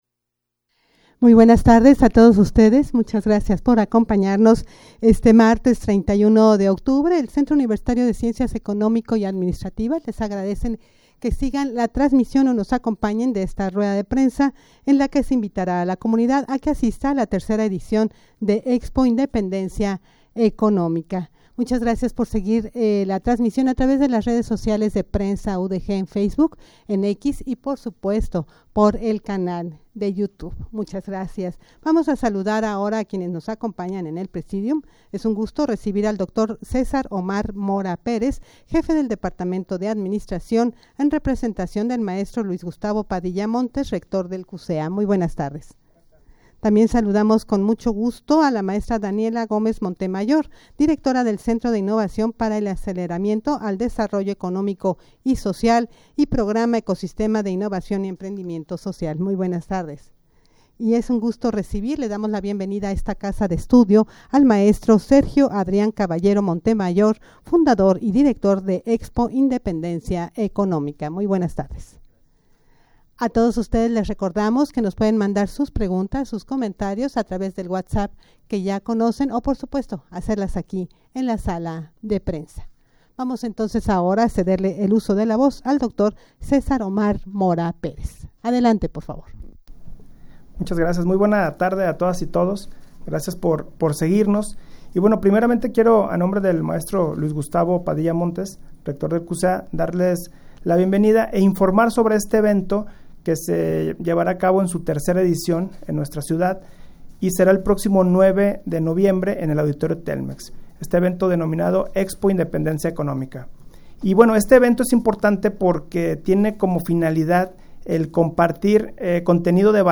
Audio de la Rueda de Prensa
rueda-de-prensa-en-la-que-se-invitara-a-la-comunidad-a-que-asista-a-la-3ra-edicion-de-expo-independencia-economica.mp3